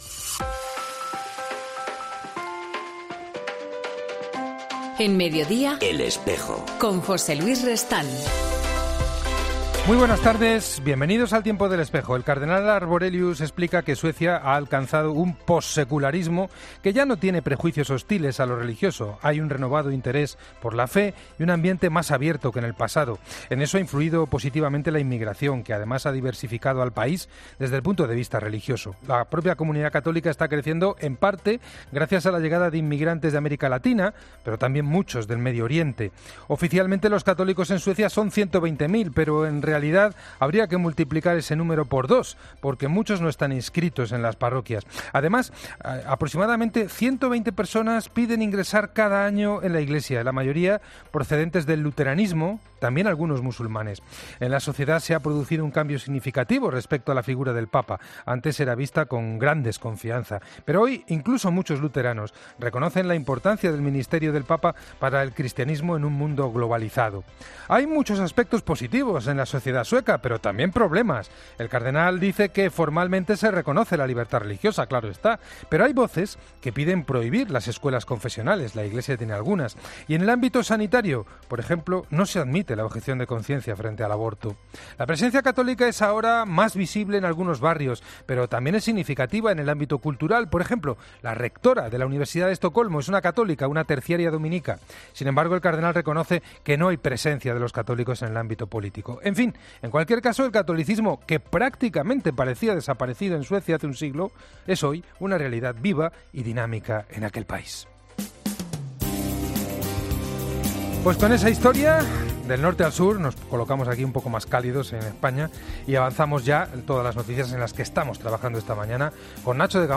AUDIO: Hoy en 'El Espejo' hablamos con